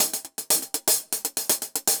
Index of /musicradar/ultimate-hihat-samples/120bpm
UHH_AcoustiHatC_120-05.wav